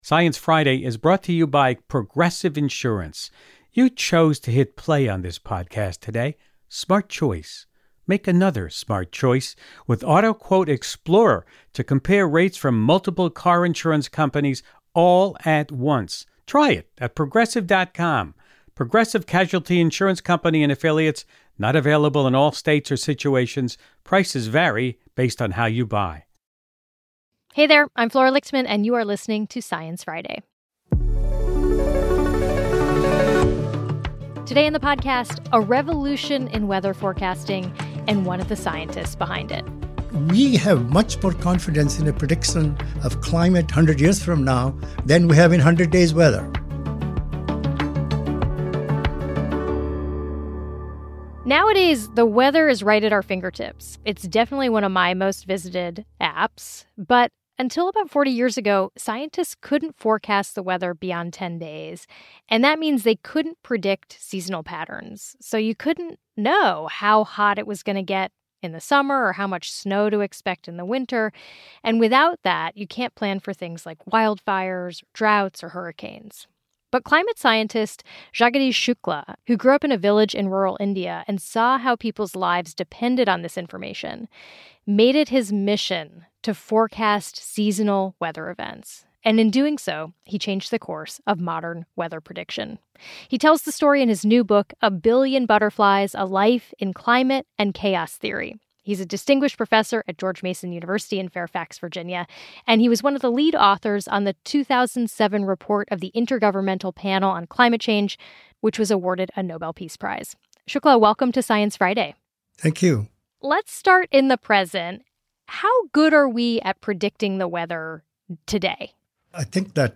Shukla became a pioneer in modern weather forecasting, and he tells his unlikely story in his new memoir, A Billion Butterflies: A Life in Climate and Chaos Theory. He talks with Host Flora Lichtman about his journey to becoming a leading climate scientist, the state of weather forecasting today, and why for